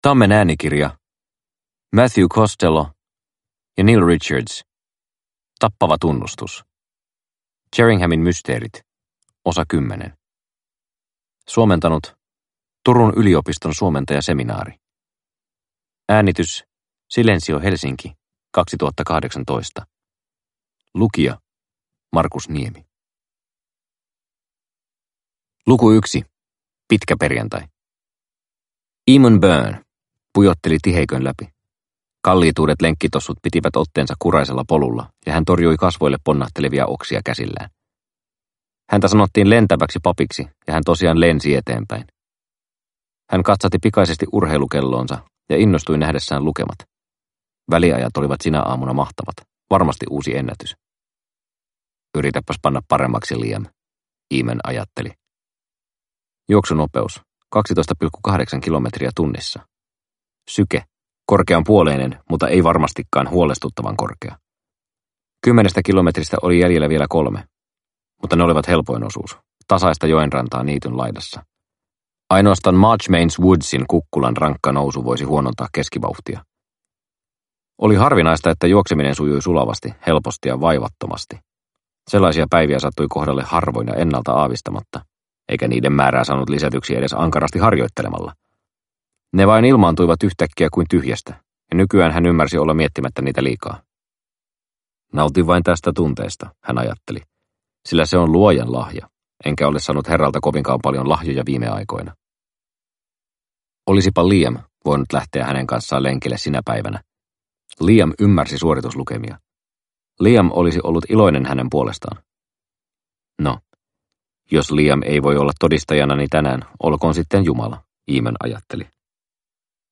Tappava tunnustus – Ljudbok – Laddas ner